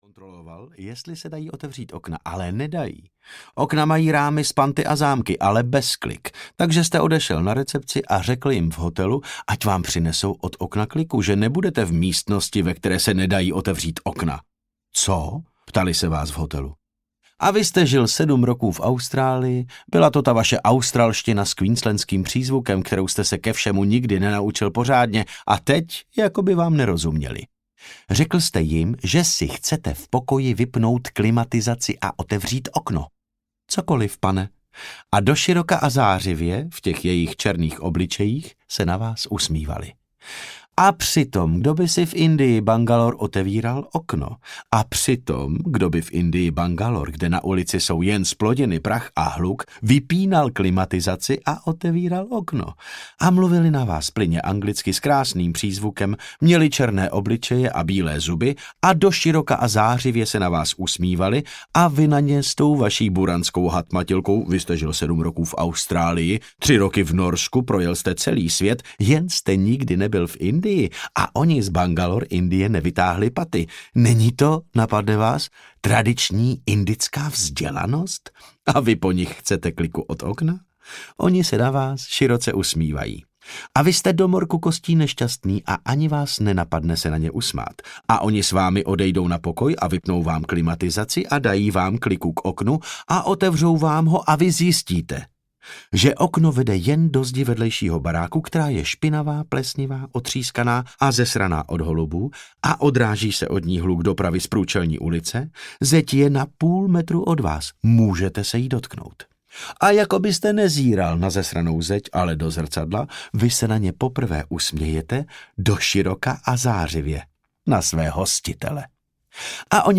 Láska v době globálních klimatických změn audiokniha
• InterpretDavid Novotný